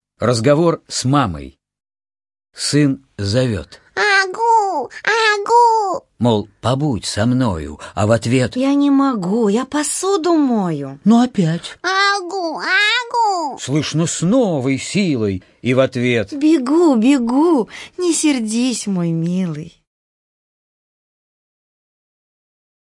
Слушайте аудиостихотворение «Разговор с мамой» Барто А. Л. на сайте Минисказка!